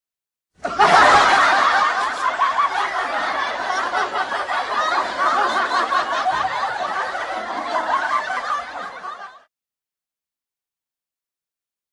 دانلود صدای خندیدن جمعیت و مردم 1 از ساعد نیوز با لینک مستقیم و کیفیت بالا
جلوه های صوتی